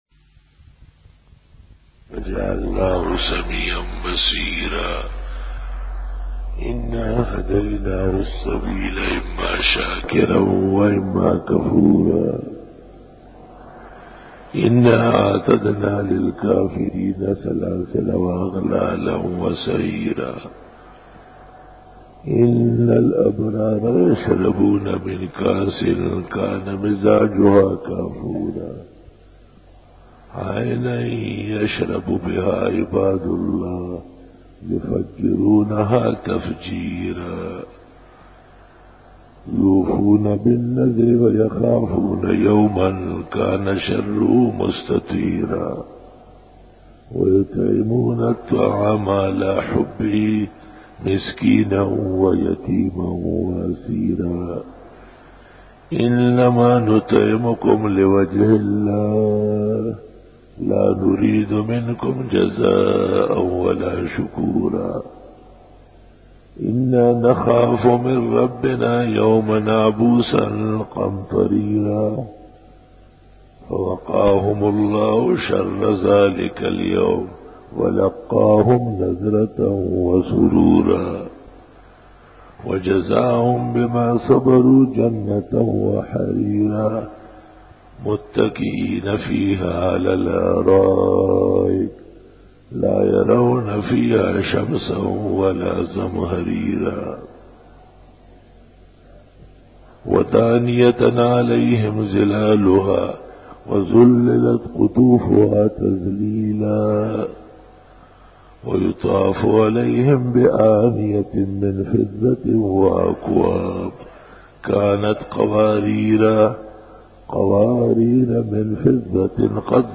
025_Jummah_Bayan_28_Jun_2002
Khitab-e-Jummah 2002